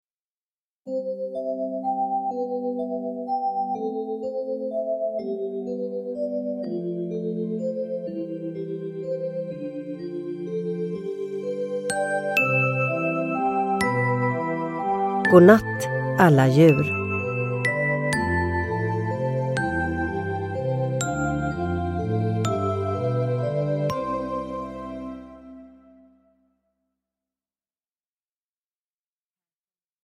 Godnatt alla djur – Ljudbok – Laddas ner
Uppläsare: Sissela Kyle